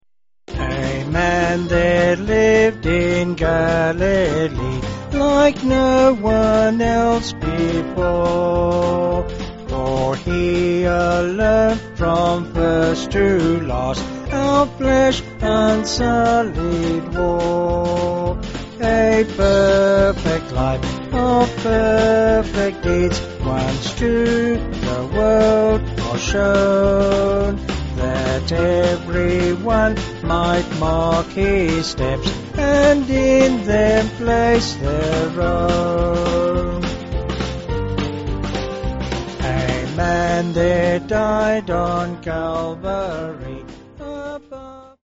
Vocal/Choral
Vocals and Band